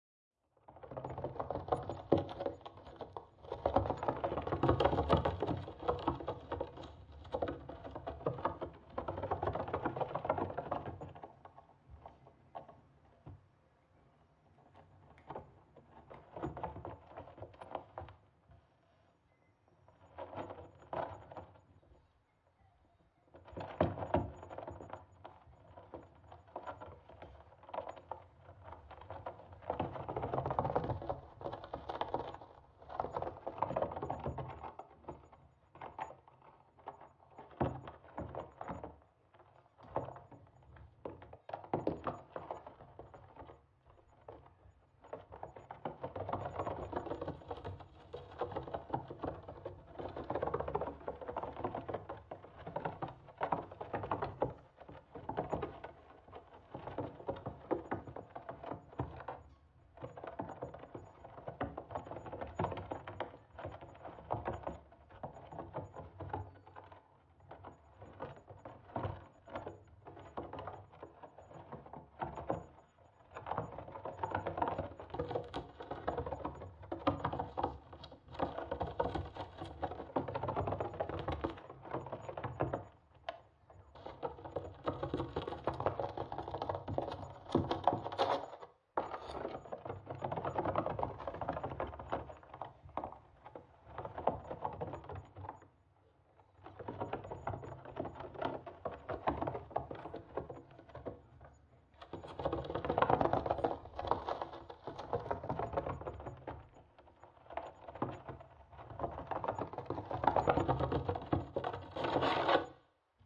На этой странице собраны разнообразные звуки, издаваемые опоссумами: от защитного шипения до тихих щелчков.
Шуршание по крыше: вероятно, на чердаке опоссум